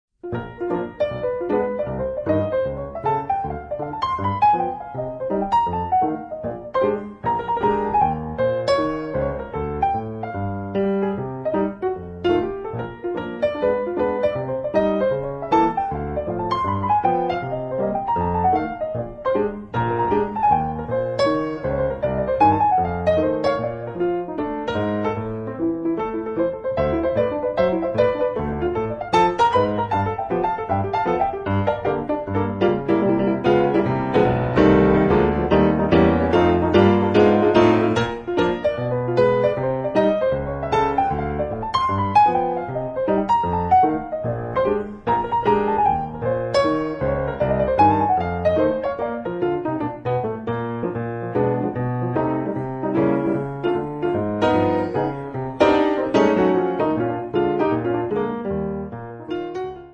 pianoforte
nello stride piano per un ragtime gustoso